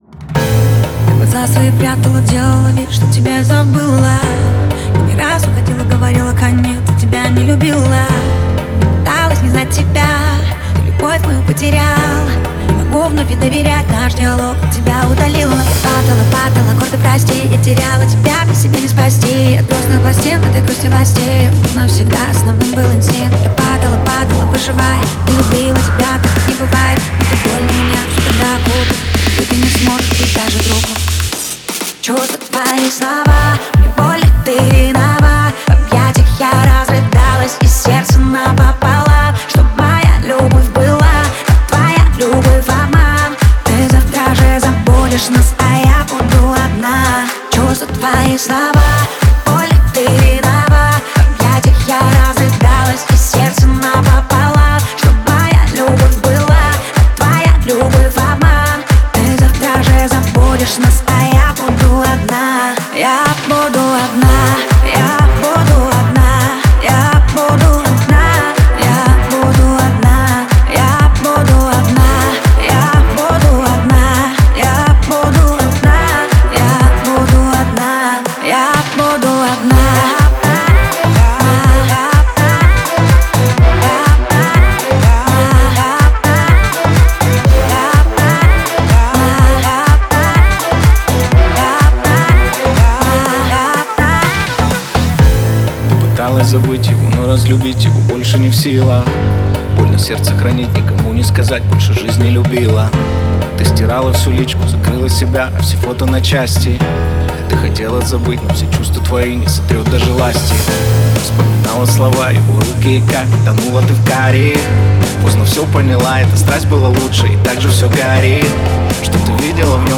это современный поп-трек с элементами электроники